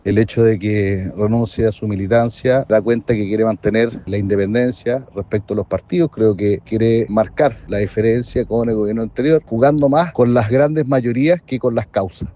Asimismo, desde la UDI, el diputado Juan Manuel Fuenzalida destacó que con esto el presidente electo buscaría comenzar a diferenciarse de la administración de Gabriel Boric.